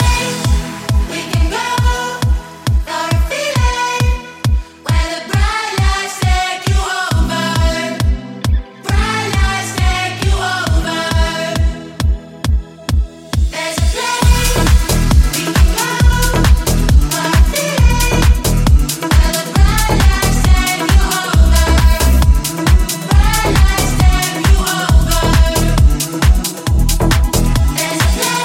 Genere: pop,deep,dance,disco,news